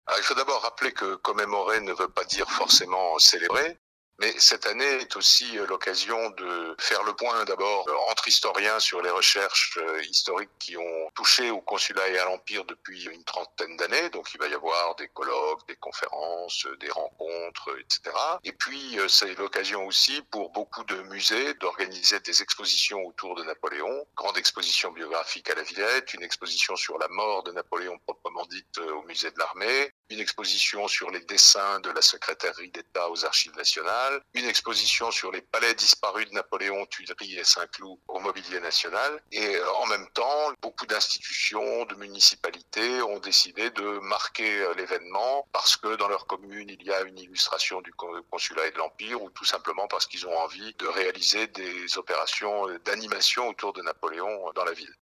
Genre : Blues.